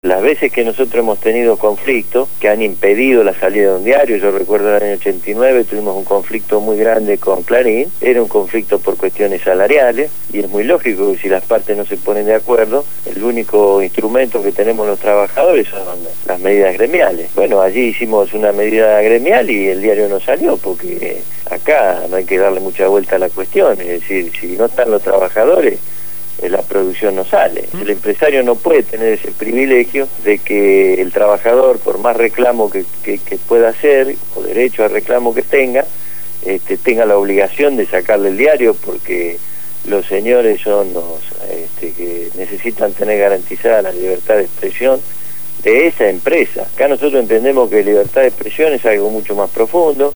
Lo hizo en el programa «Punto de partida» (Lunes a viernes de 7 a 9 de la mañana) por Radio Gráfica FM 89.3